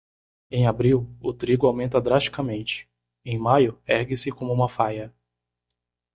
Pronounced as (IPA) /ˌdɾas.t͡ʃi.kaˈmẽ.t͡ʃi/